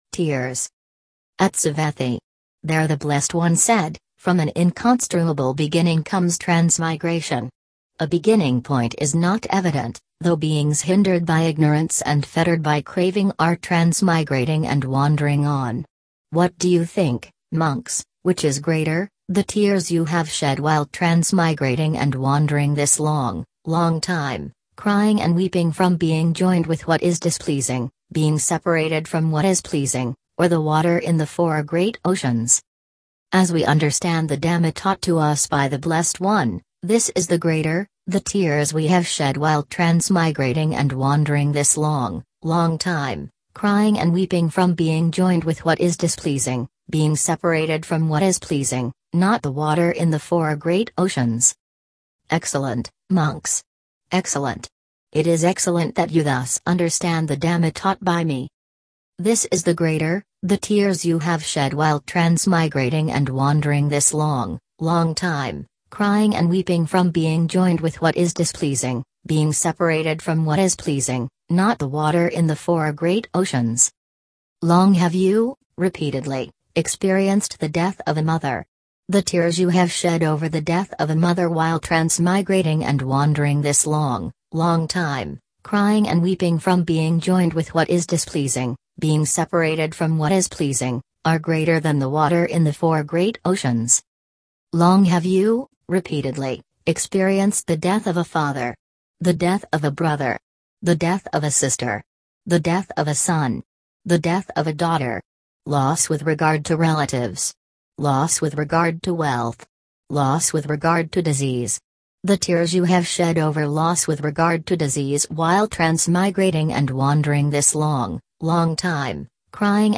Text Reader Online
This one seems to have better pronunciation. This is with the Alice voice.